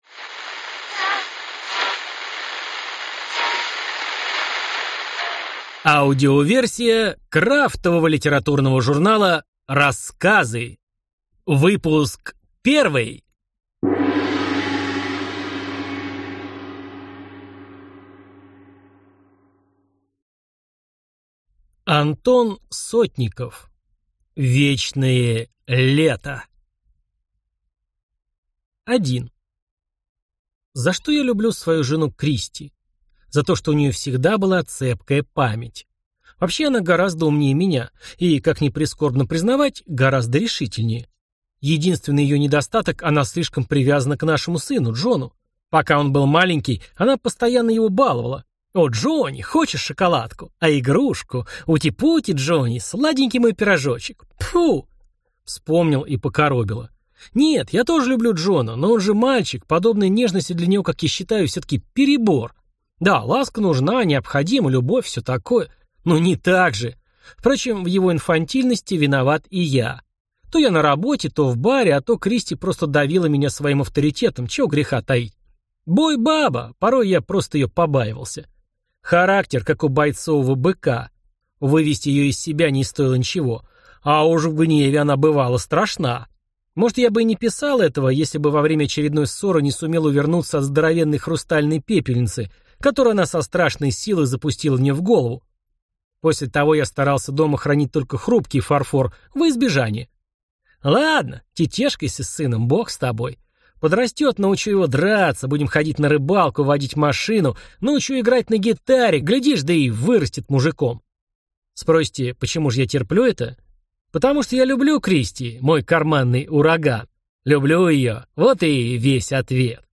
Аудиокнига Рассказы 1 | Библиотека аудиокниг